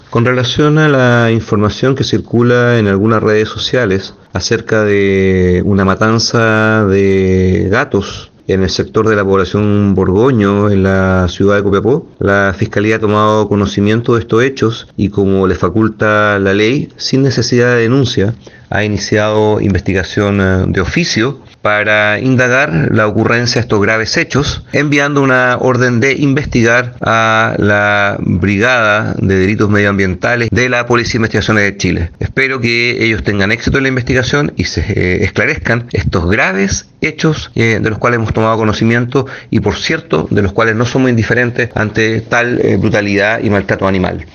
AUDIO-FISCAL-CHRISTIAN-GONZALEZ-CARRIEL.mp3